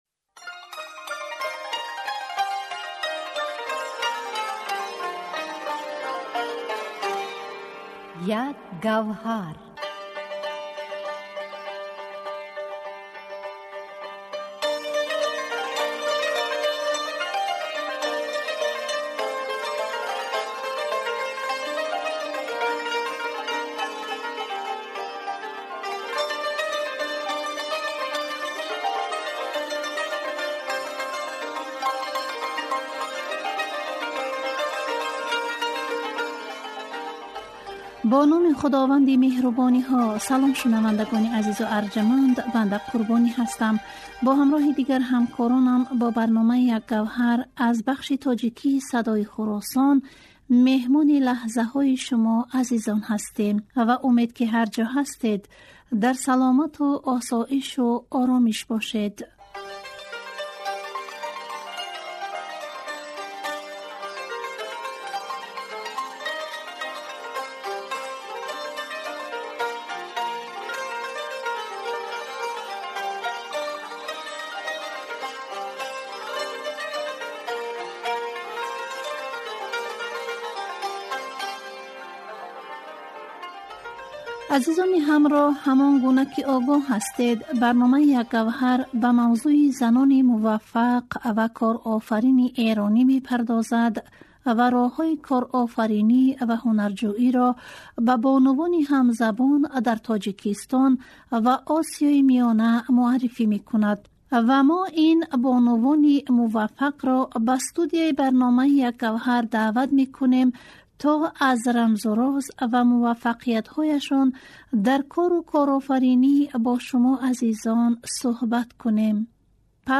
Дар силсилабарномаҳои "Як гавҳар", ҳар ҳафта ба масоили марбут ба занони эронӣ пардохта мешавад ва роҳҳои корофаринӣ ва ҳунарҷӯиро ба занони тоҷик ва Осиёи Миёна муъаррифӣ мекунад. Ин барнома, панҷшанбеи ҳар ҳафта аз Садои Хуросон пахш мешавад.